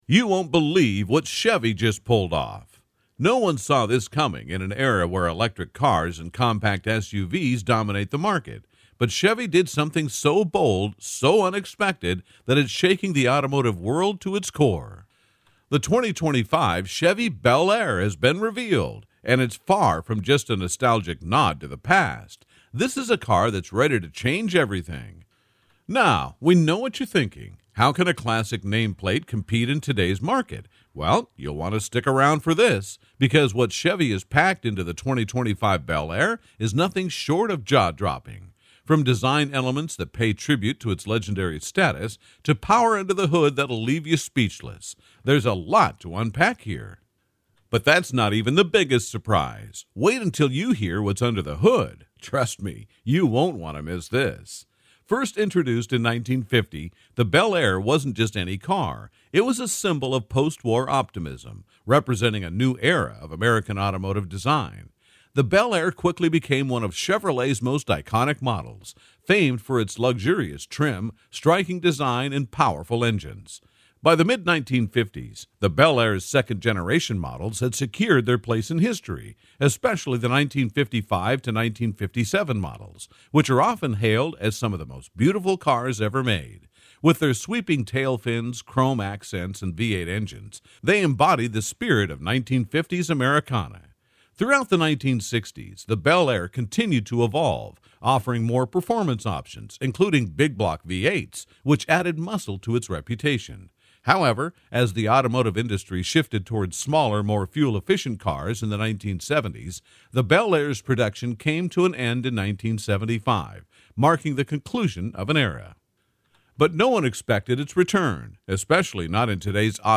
Automotive Video Sample
English - Midwestern U.S. English